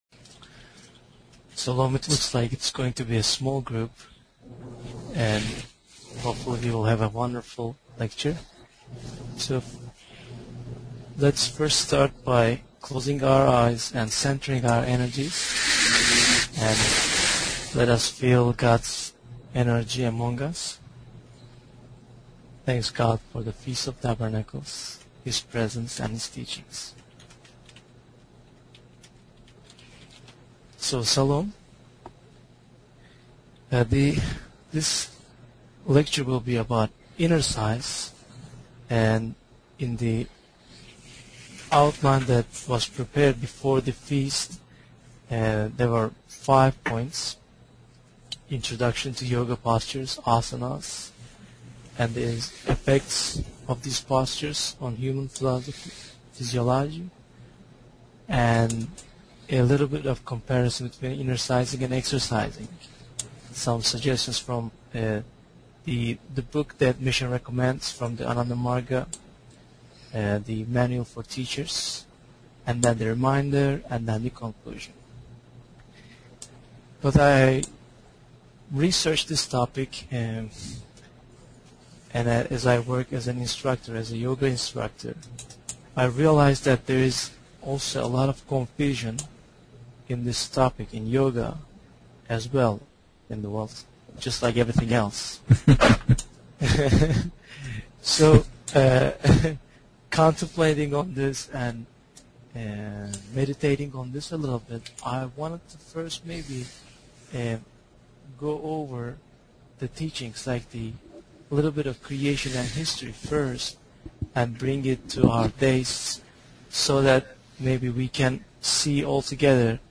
Audio_Innercise_Lecture_Unus.mp3